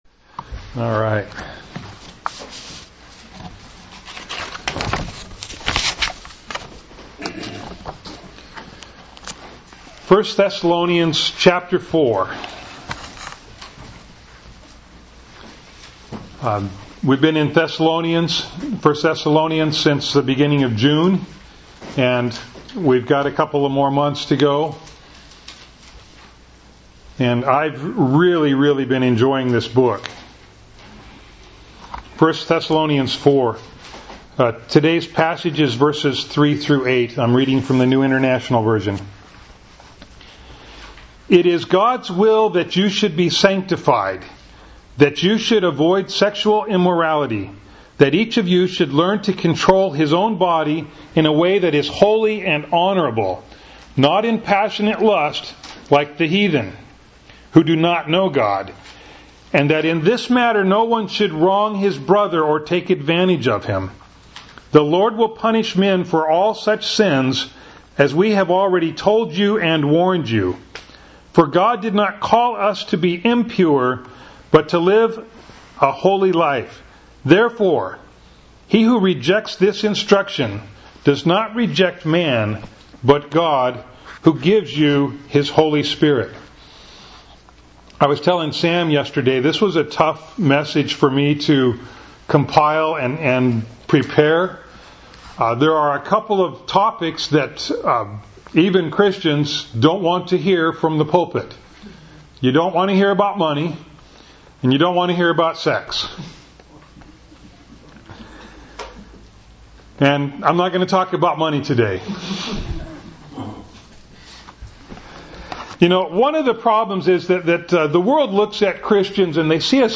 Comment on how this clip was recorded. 1 Thessalonians 4:3-8 Service Type: Sunday Morning Bible Text